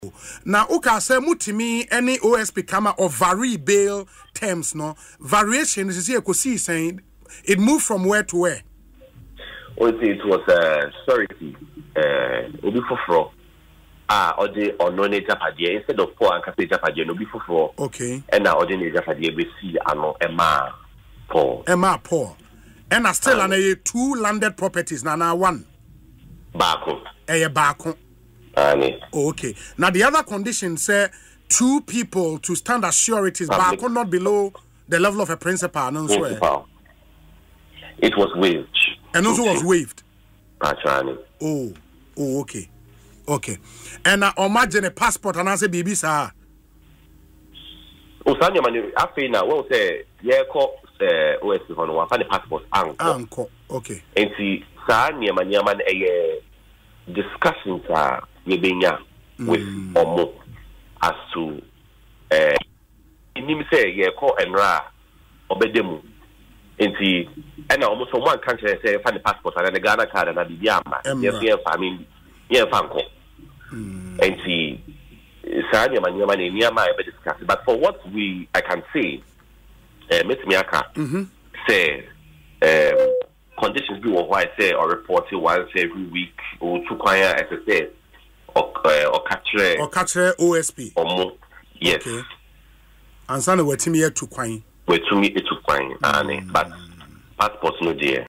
confirmed the development in an interview on Asempa FM’s Ekosii Sen